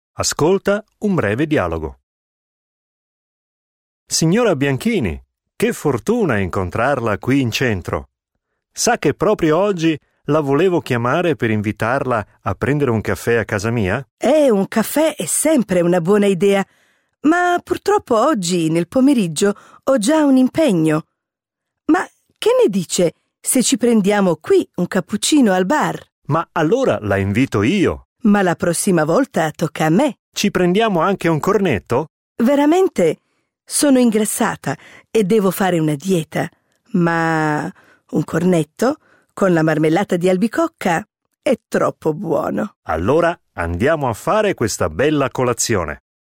Hörverständnis